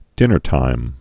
(dĭnər-tīm)